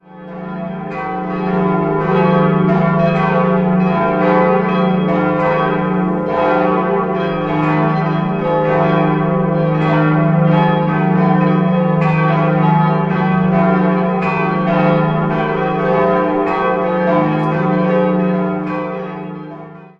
Jahrhundert eine barocke Fassade im Osten angefügt wurde. 5-stimmiges Geläut: e'-fis'-gis'-dis''-g'' Die beiden großen Glocken wurden im 14. Jahrhundert, die kleinste 1540 in Nürnberg gegossen.